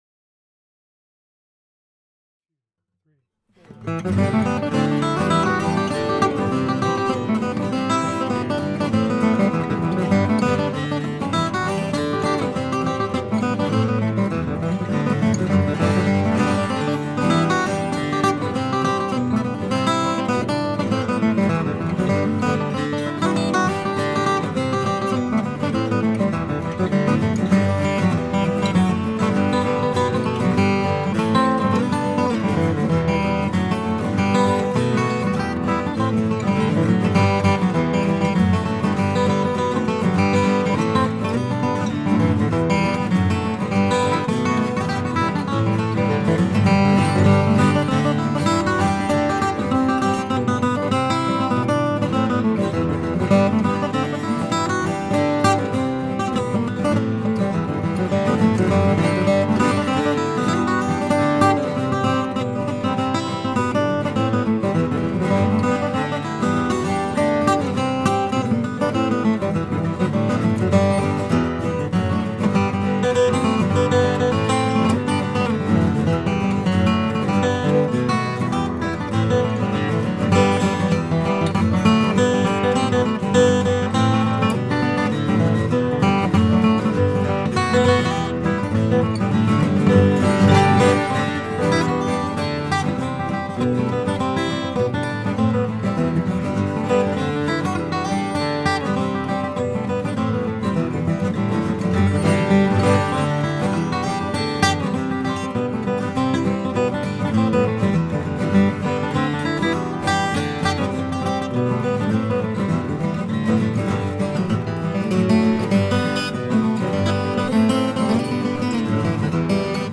This is kinda crazy sounding.
A chorus of guitars.
All the guitars are on rhythm all the time.